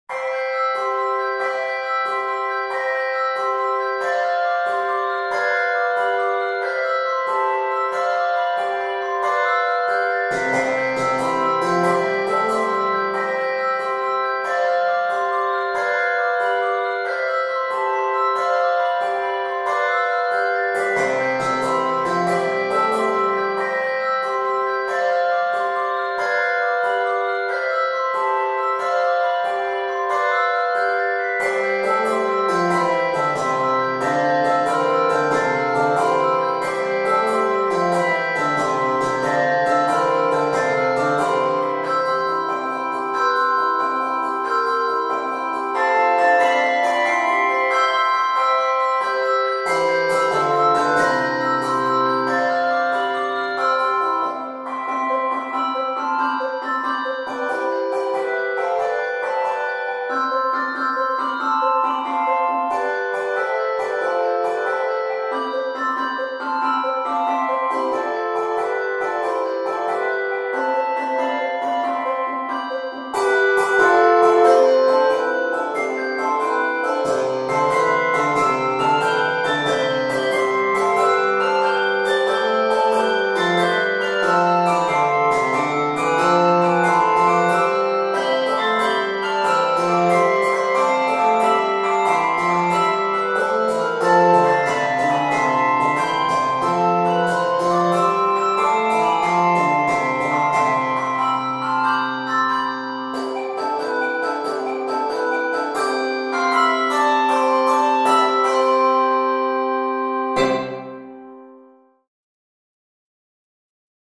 Scored in C major.